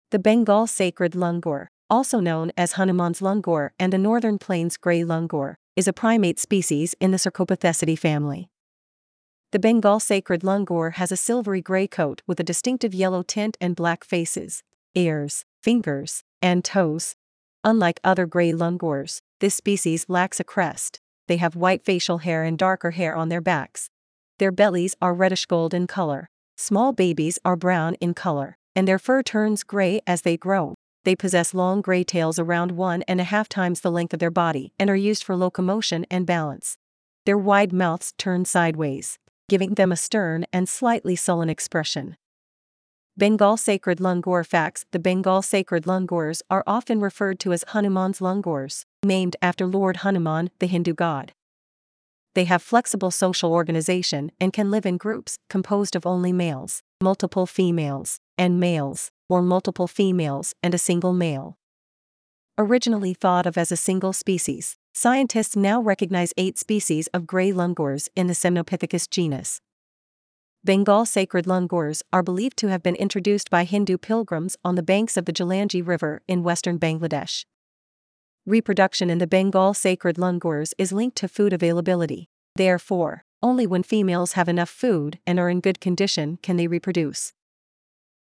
Bengal-Sacred-Langur.mp3